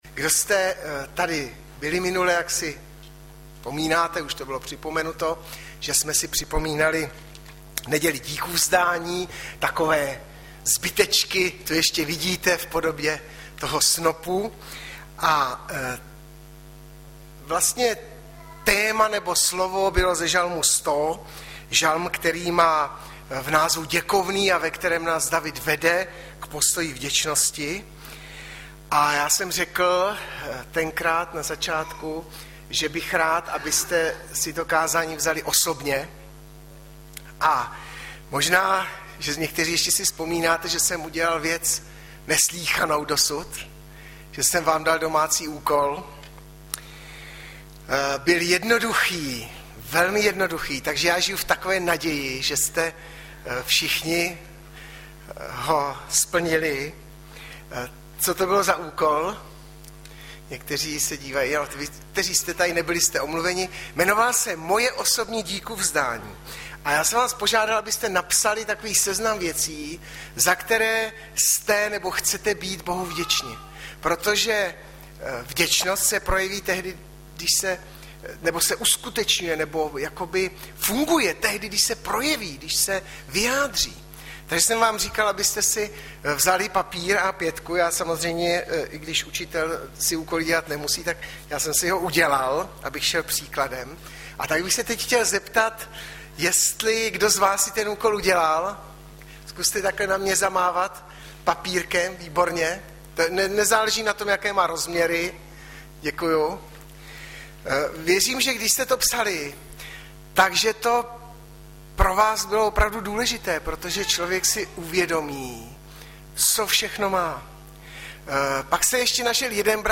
Hlavní nabídka Kázání Chvály Kalendář Knihovna Kontakt Pro přihlášené O nás Partneři Zpravodaj Přihlásit se Zavřít Jméno Heslo Pamatuj si mě  21.10.2012 - SVĚDECTVÍ - Mk 5,1-20 Audiozáznam kázání si můžete také uložit do PC na tomto odkazu.